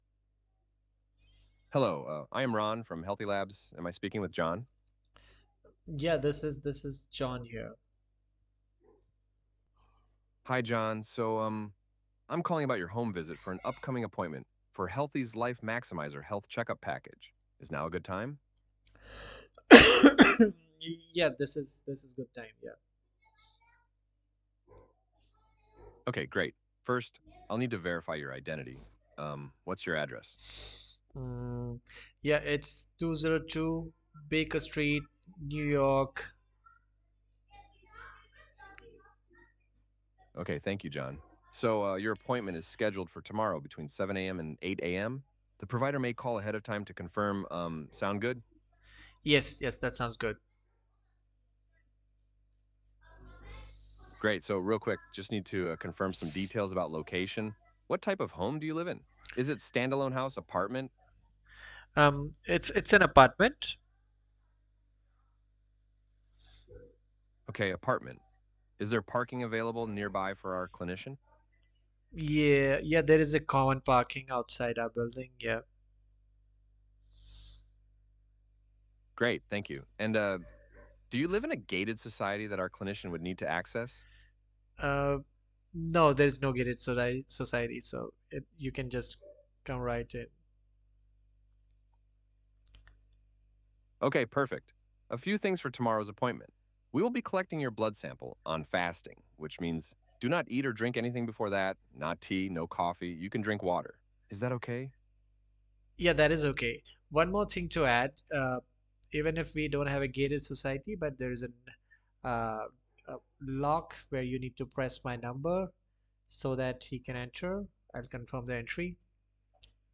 Live Voice Bot Demo - Latency, Accuracy, Intelligence
Health care Appointment Confirmation & Pre‑Visit Instructions
AI Voice Bot with Personality & Sentiment Awareness.
Apisdor-AI-ron-for-Healthys-Lab-with-a-personality-and-sentimental-analysis.mp3